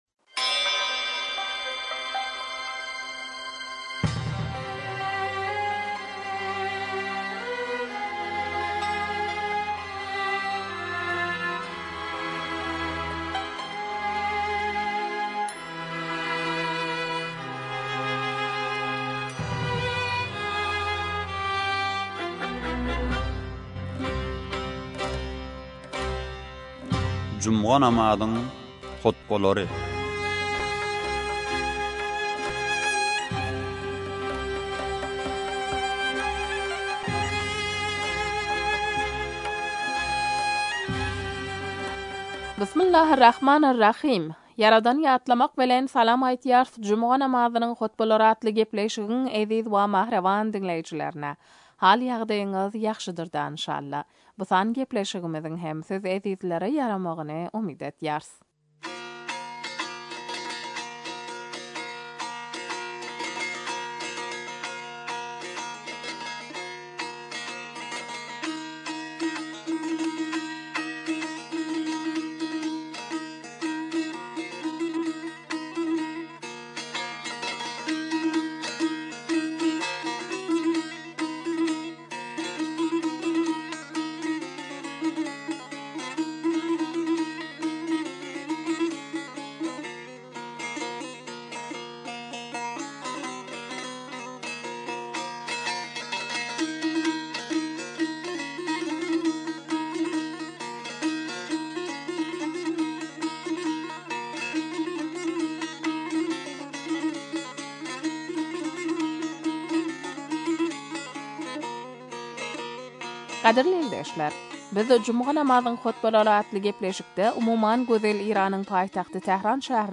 juma namazynyň hutbalary